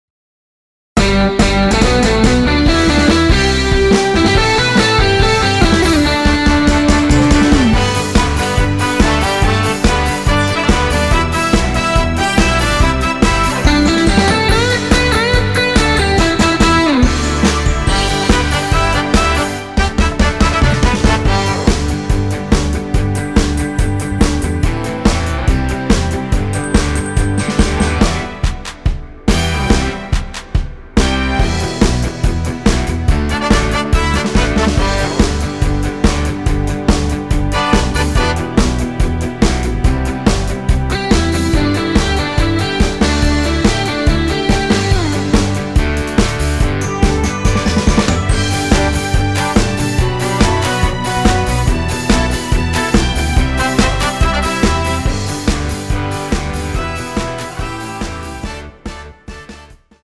원키에서(-1) 내린 하이퀄리티 MR 입니다.
◈ 곡명 옆 (-1)은 반음 내림, (+1)은 반음 올림 입니다.
앞부분30초, 뒷부분30초씩 편집해서 올려 드리고 있습니다.